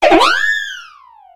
WHIMSICOTT.ogg